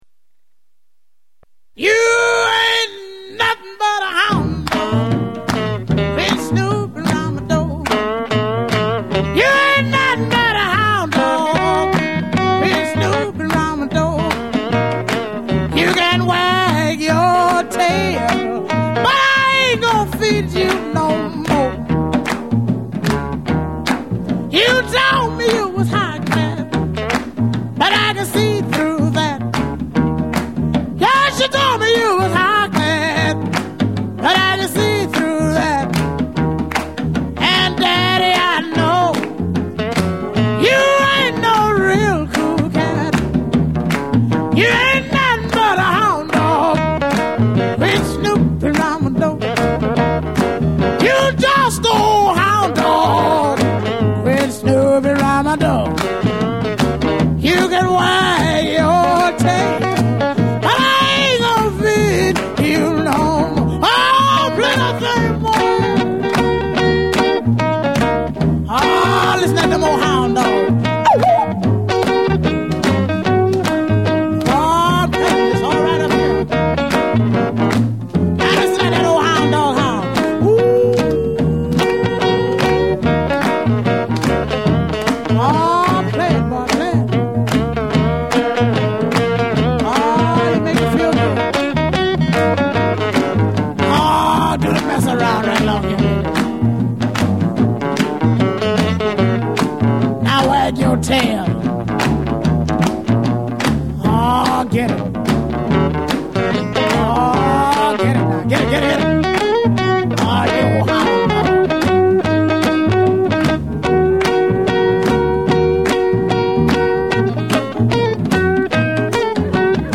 magnificent voice, infinitely tender and sad